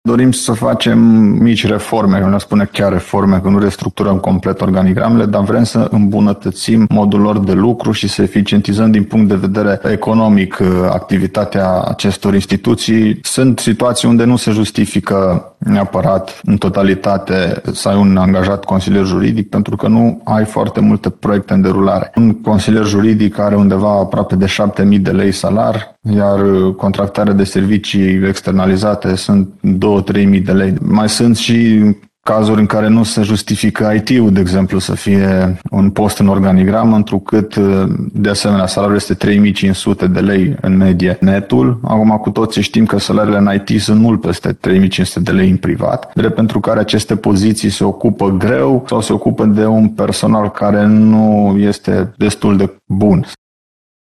Spre exemplu, la Biblioteca Județeană Timiș, autoritățile au ajuns la concluzia că e mai ieftin să externalizeze serviciile de consultanță juridică, decât să plătească un consilier angajat în acest sens, spune vicepreședintele CJ Timiș, Alexandru Proteasa.